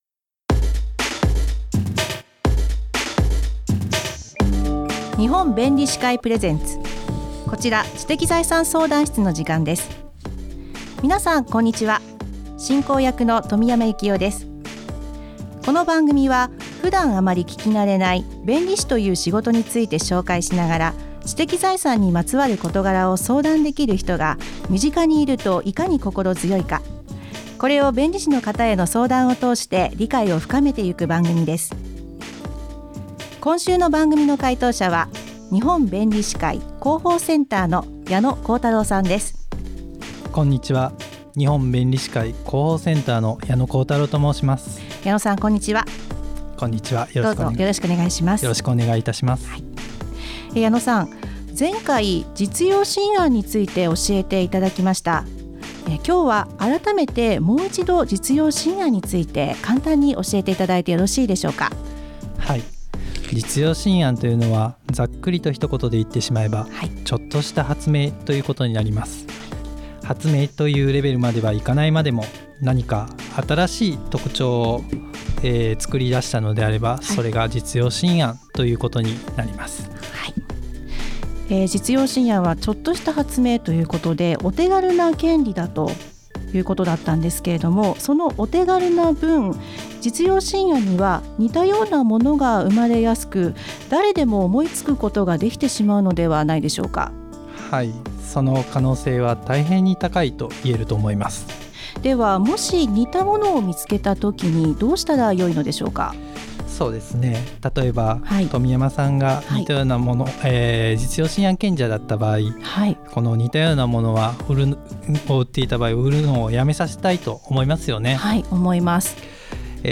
日本弁理士会提供ラジオ番組にて放送しました。
知的財産にまつわる質問に弁理士が答えます！